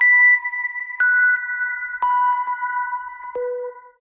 match-start.ogg